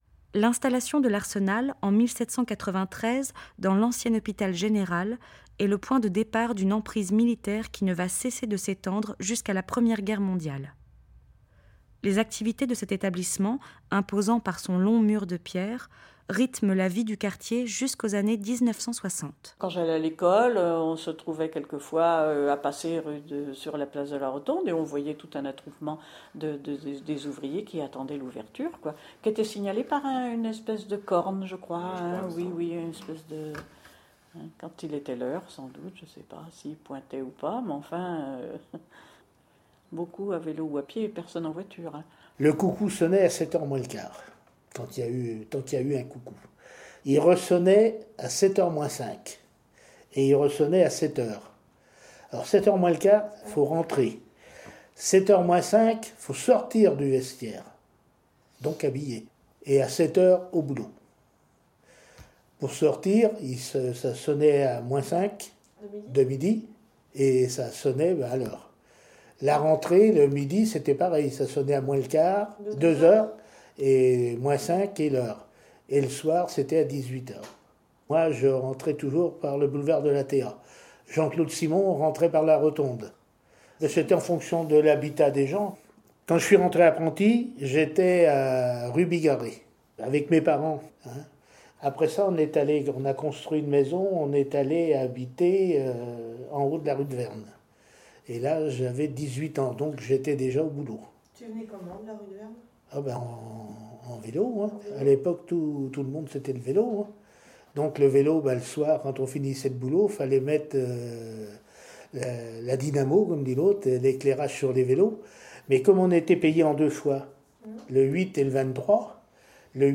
Les montages sonores